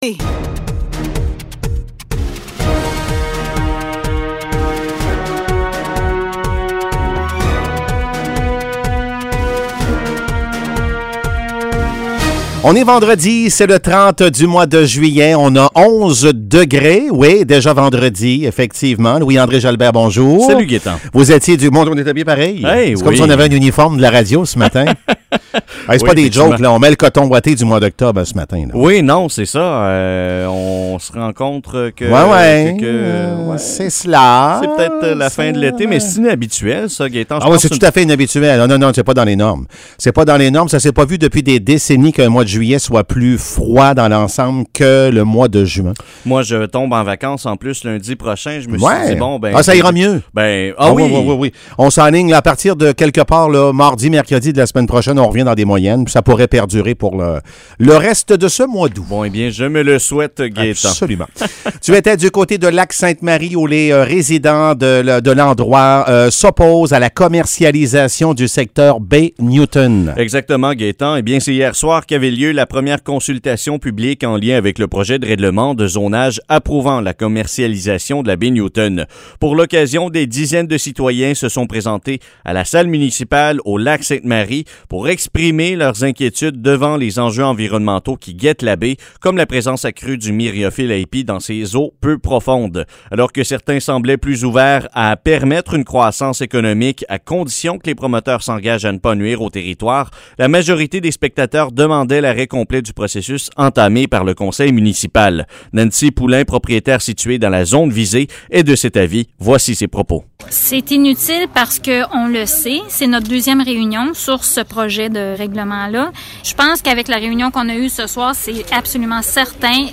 Nouvelles locales - 30 juillet 2021 - 7 h